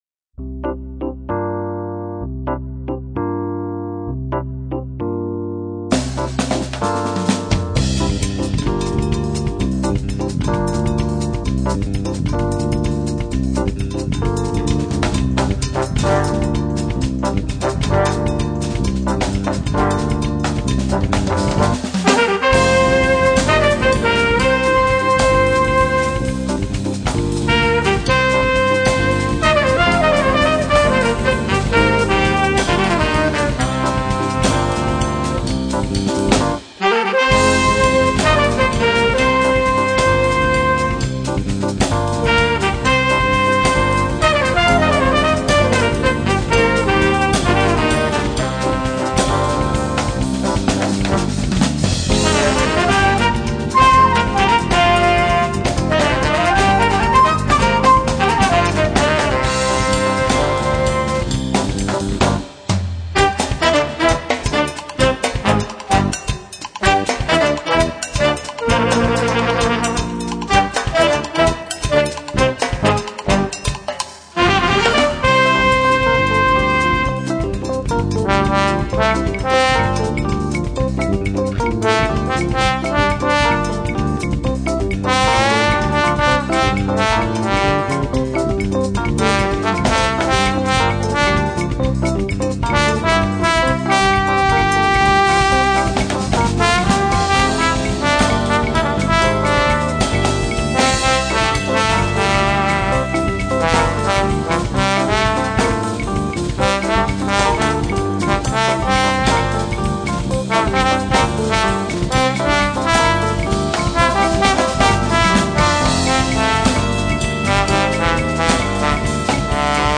Signál  šel z basy do jakéhosi lampového preampu a pak do linky.